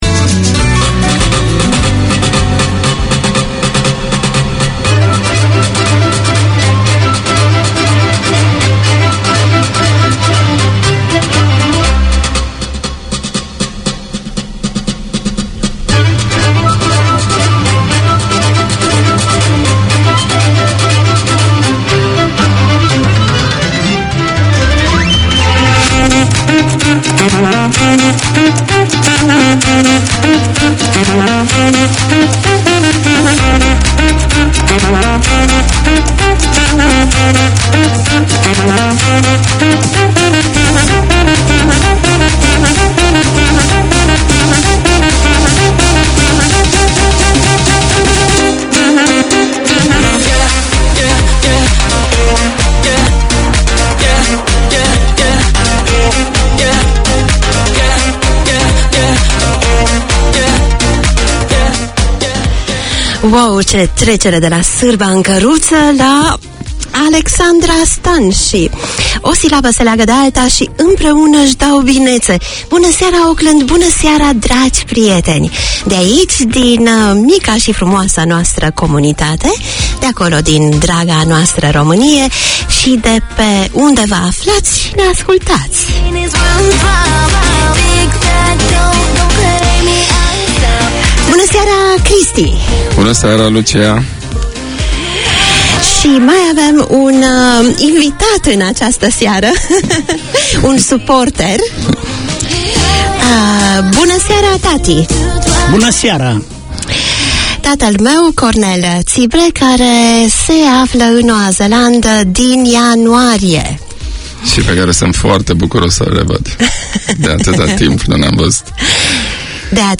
Community Access Radio in your language - available for download five minutes after broadcast.
From local legends in grassroots to national level names, the Sports Weekender features interviews, updates and 'the week that was'.